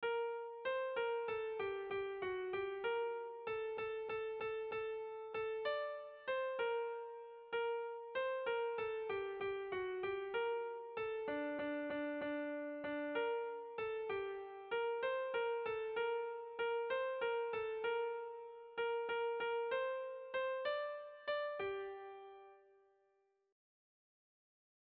Gabonetakoa
Zuberoa < Euskal Herria
Lauko handia (hg) / Bi puntuko handia (ip)
A1A2